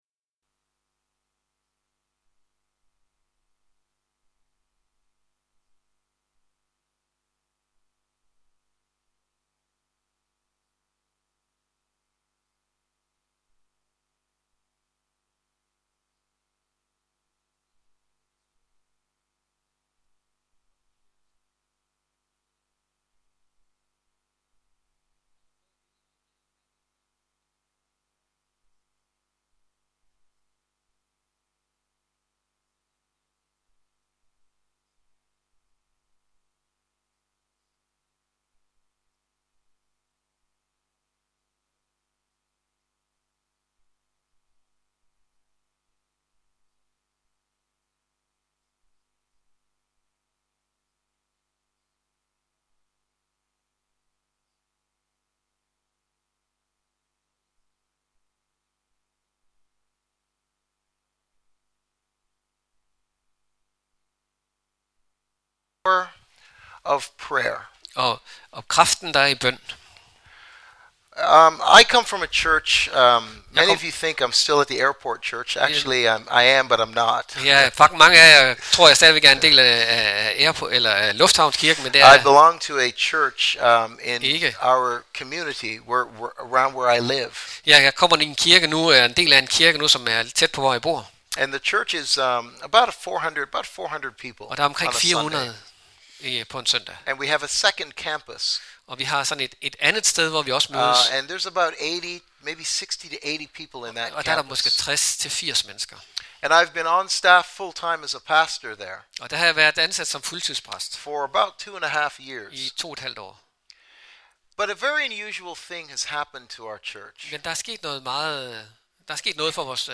Gudstjeneste